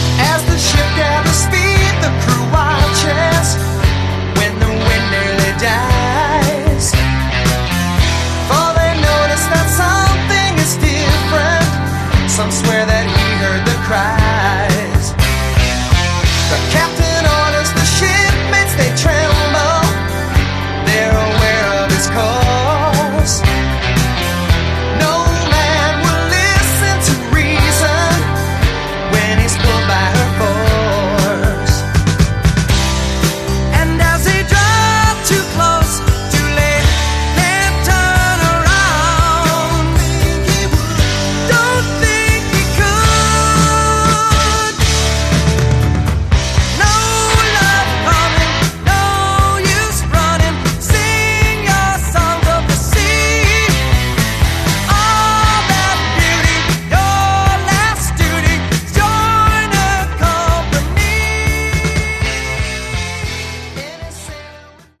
Category: AOR
vocals, bass
keyboards, guitar
drums